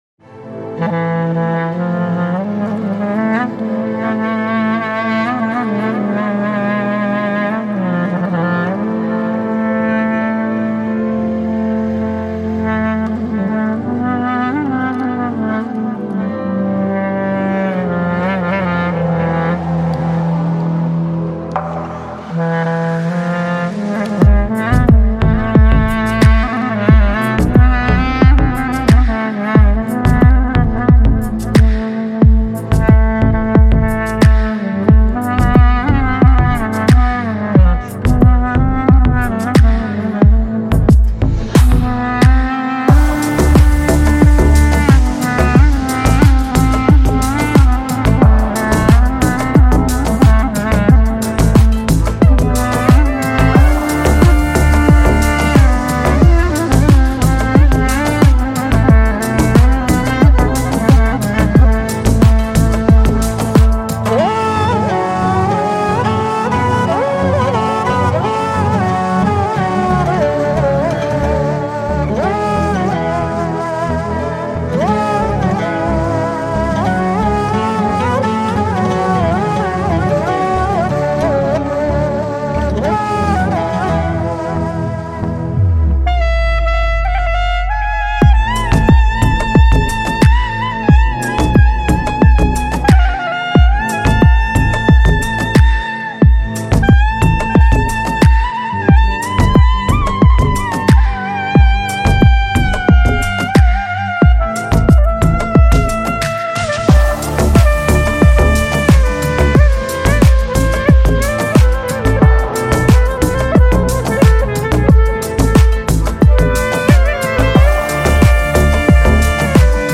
Жанр: Узбекские песни Слушали